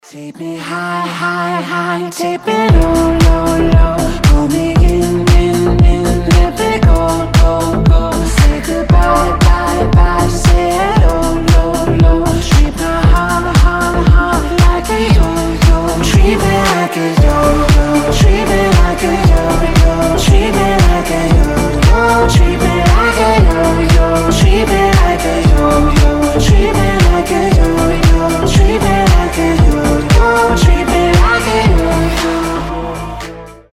танцевальные , deep house , мужской голос
мелодичные